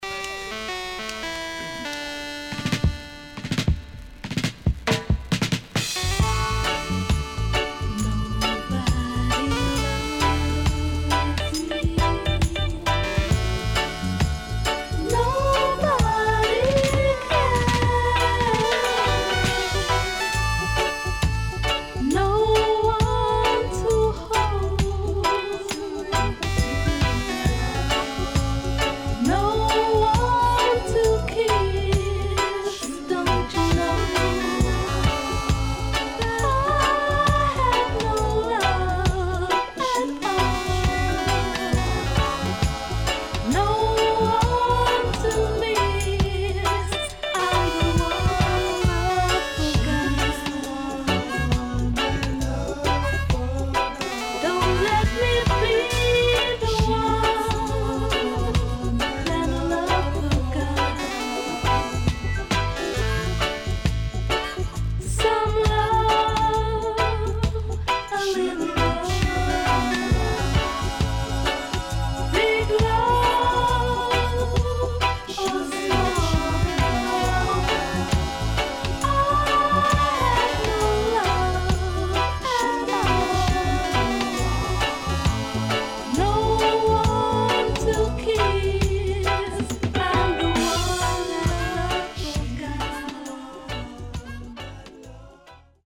SIDE B:少しチリノイズ入ります。